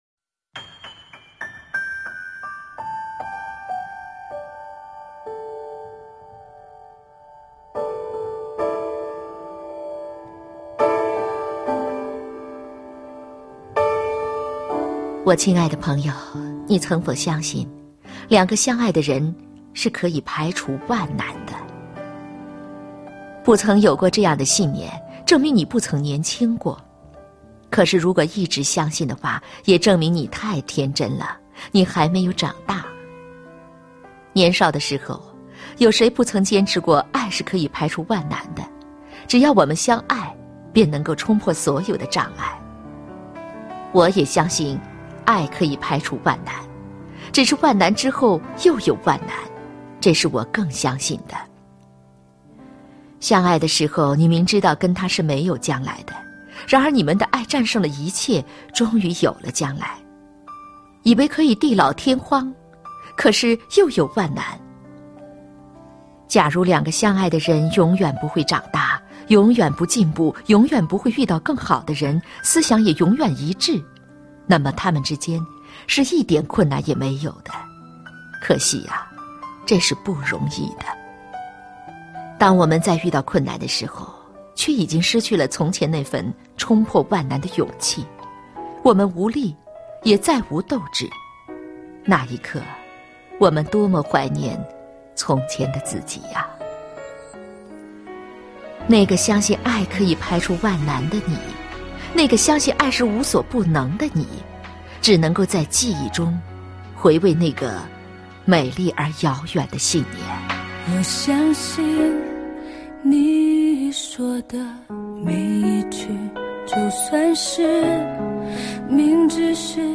经典朗诵欣赏 张小娴：爱，从来就是一件千回百转的事 目录